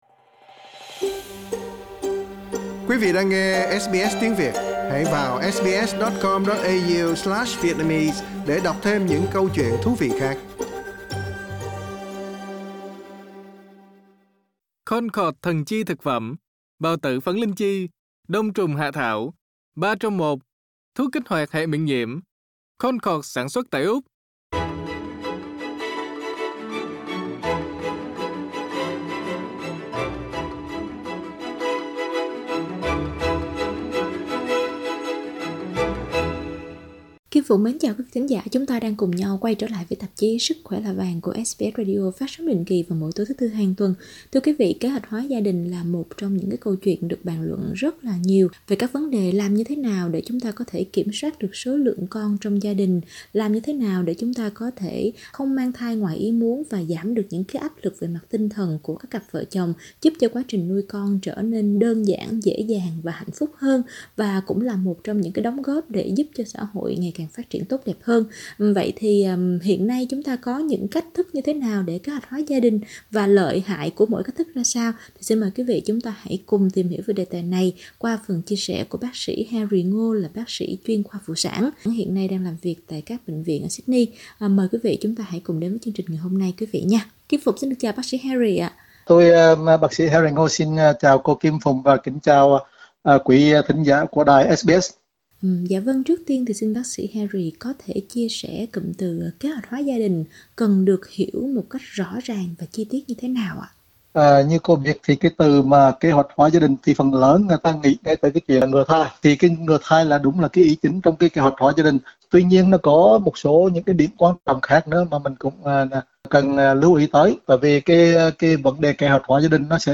chuyên khoa phụ sản trình bày chi tiết trong chương trình hôm nay.